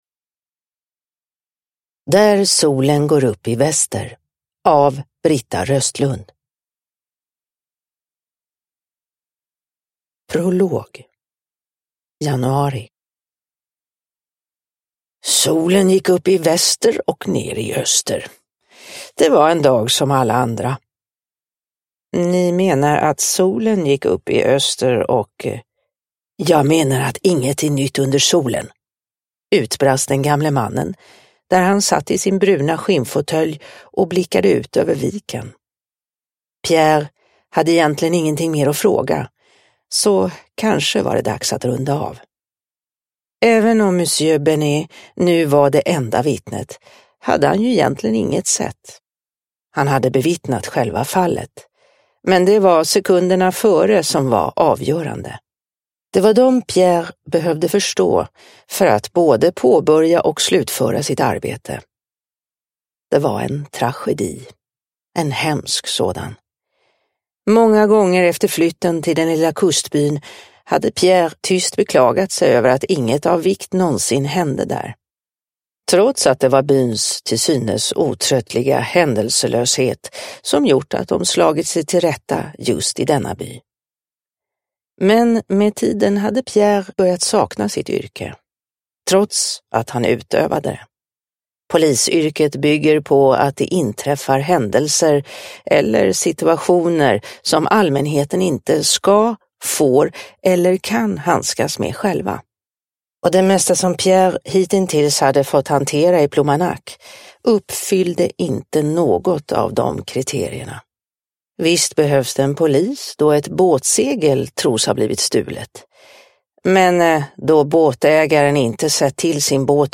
Där solen går upp i väster – Ljudbok – Laddas ner